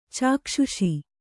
♪ cākṣuṣi